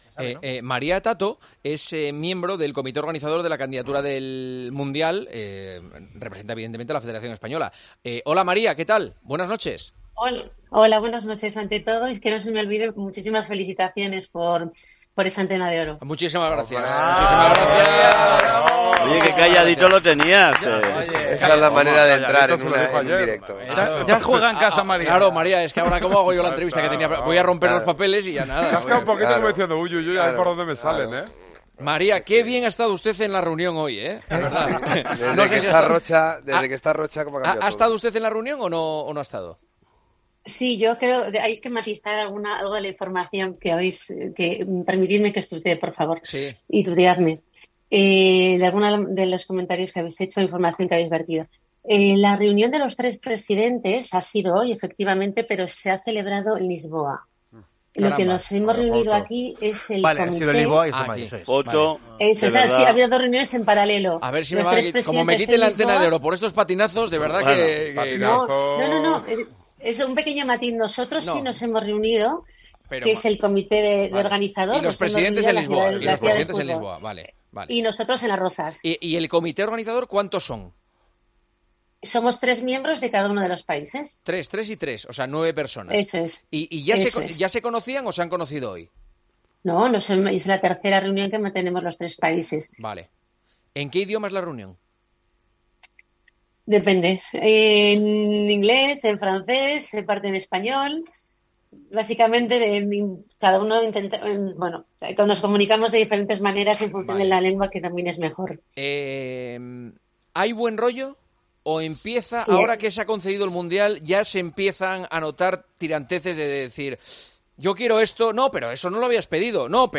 AUDIO: Entrevistamos